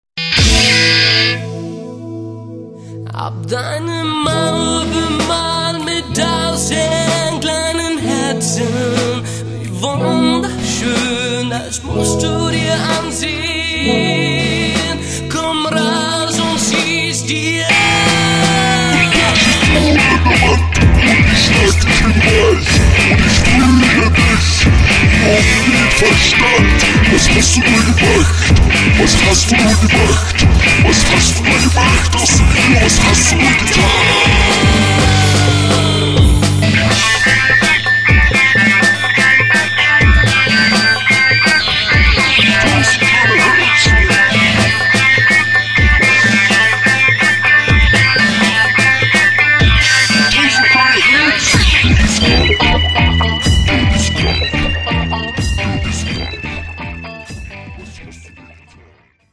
bass
drums/percussion